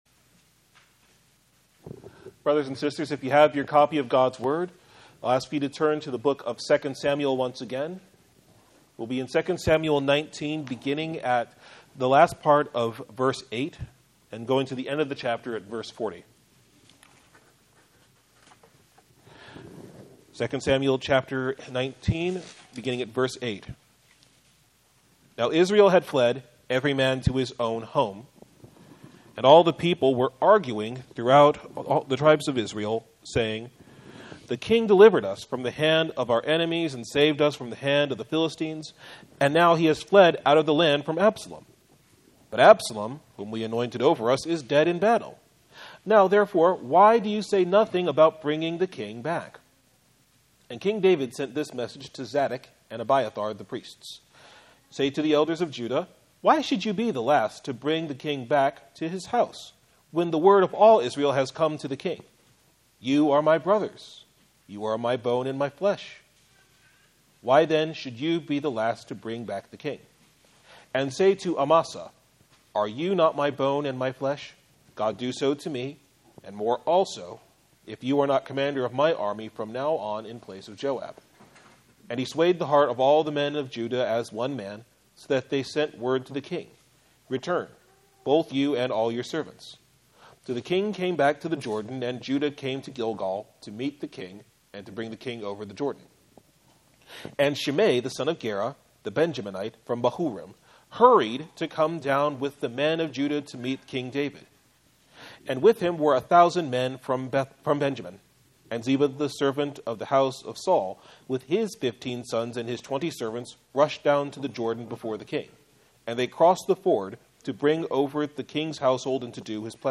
Sermon Text: 2 Samuel 19b-40 Theme: The king appointed by God to rule His people offers grace and mercy to all who will submit themselves to his reign.